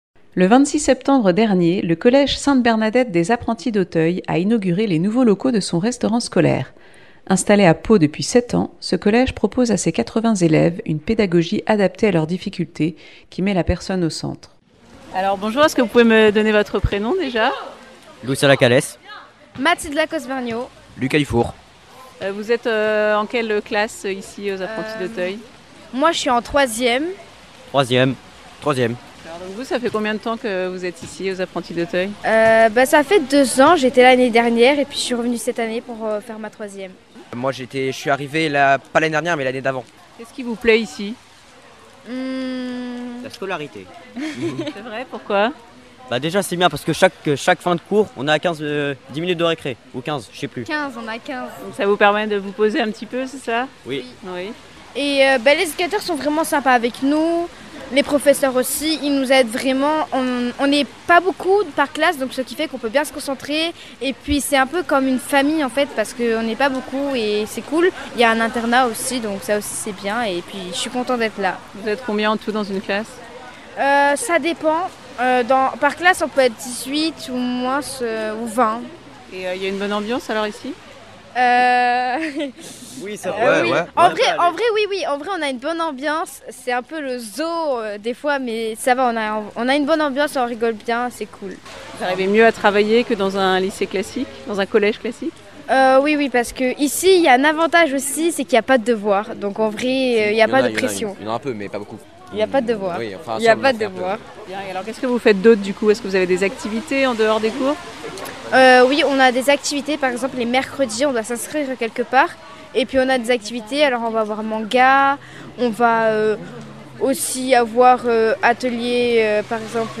Inauguration aux Apprentis d’Auteuil à Pau : reportage.